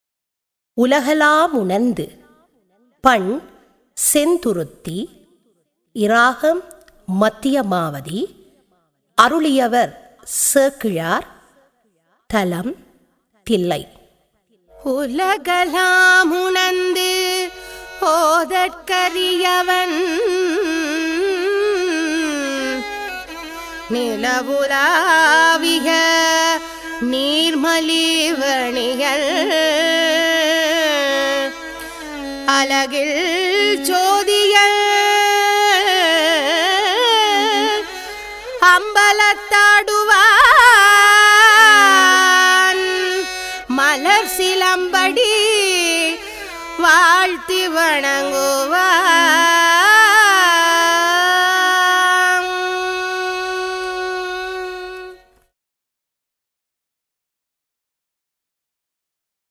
தரம் 6 இல் கல்வி பயிலும் சைவநெறிப் பாடத்தை கற்கும் மணவர்களின் நன்மை கருதி அவர்கள் தேவாரங்களை இலகுவாக மனனம் செய்யும் நோக்கில் இசைவடிவாக்கம் செய்யப்பட்ட தேவாரப்பாடல்கள் இங்கே பதிவிடபட்டுள்ளன.